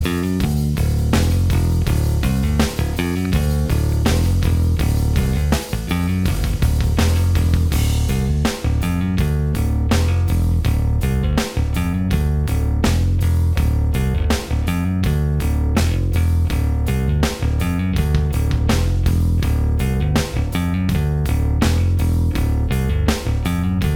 Minus All Guitars Rock 5:15 Buy £1.50